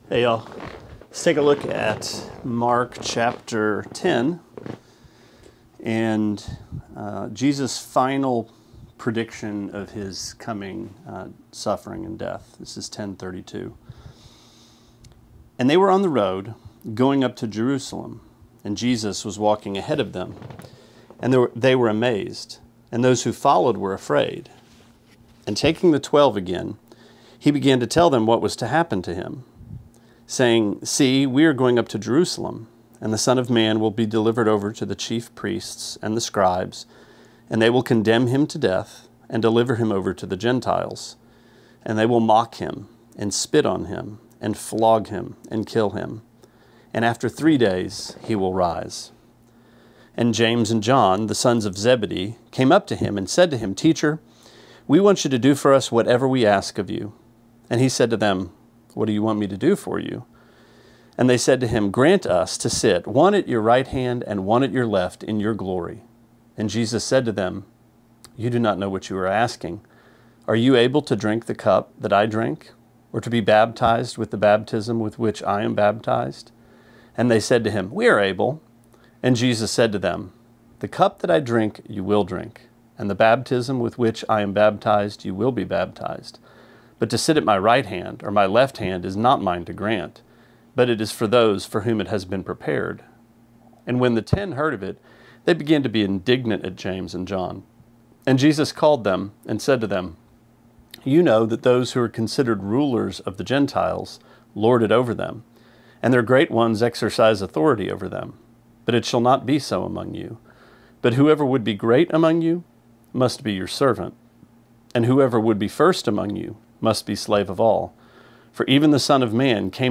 Sermonette 7/25: Mark 10:32-45: The Cup and the Bath